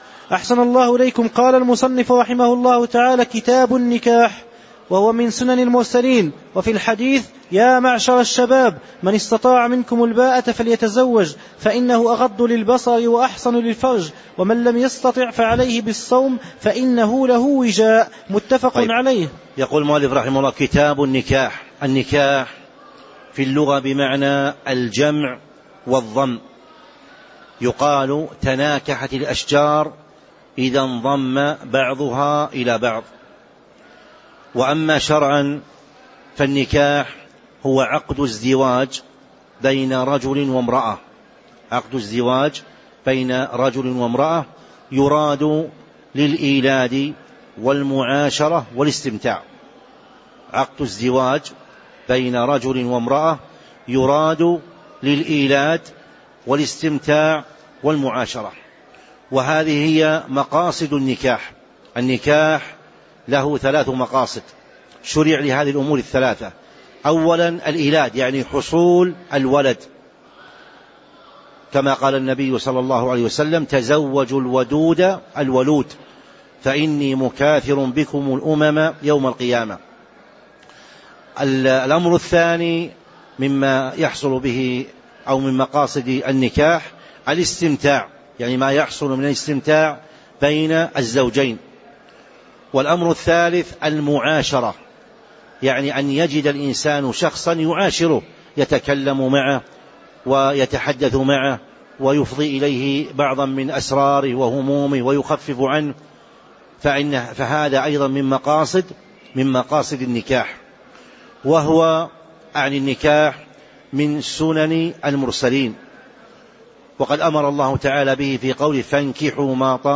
تاريخ النشر ٢٤ جمادى الأولى ١٤٤٦ هـ المكان: المسجد النبوي الشيخ